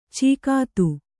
♪ cīkātu